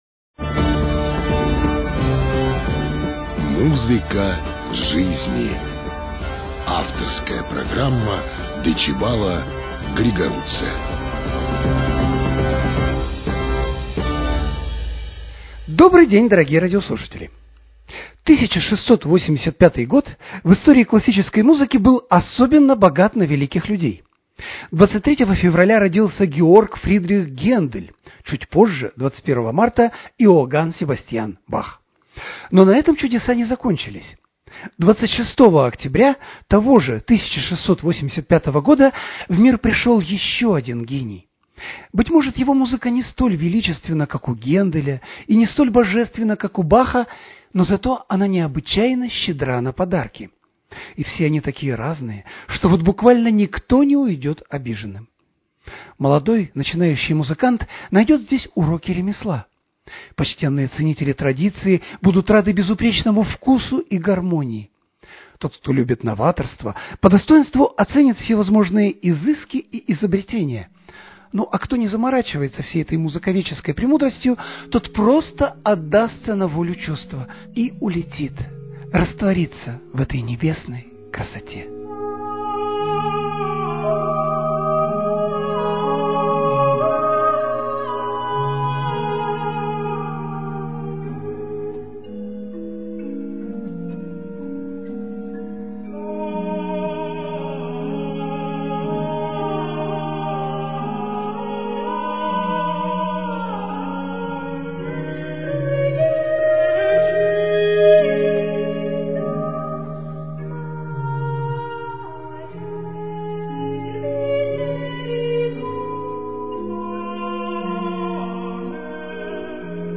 Познавательно – просветительская авторская передача музыковеда